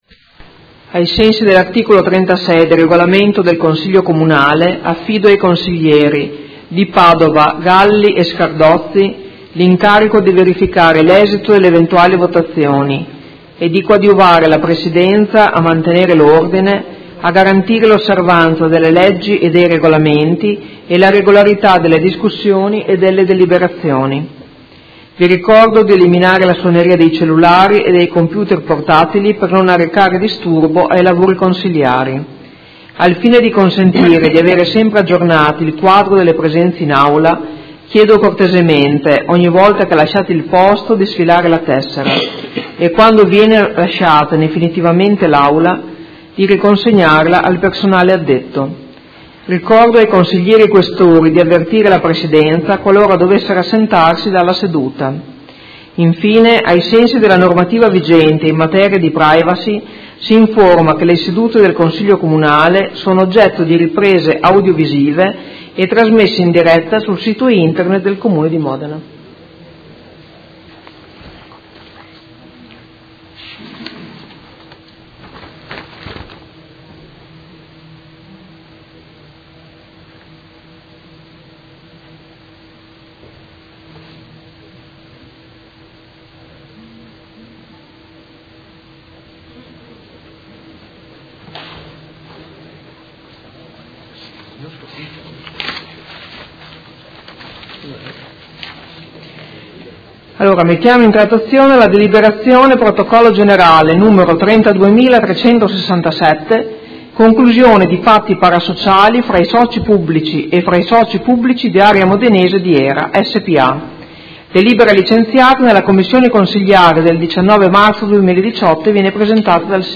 Seduta del 5/04/2018. Apertura lavori